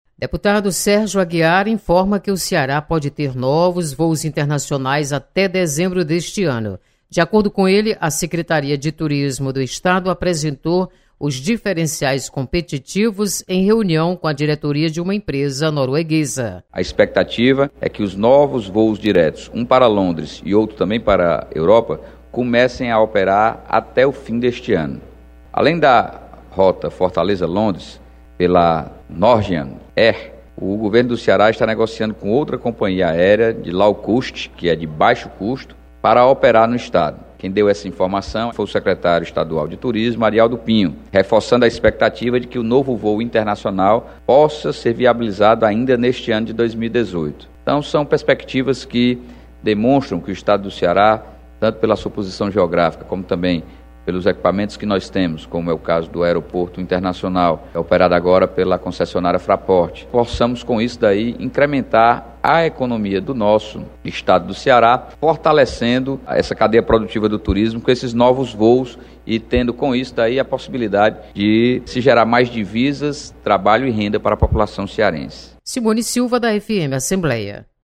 Deputado Sérgio Aguiar comemora chegada de novos voos internacionais ao Ceará. Repórter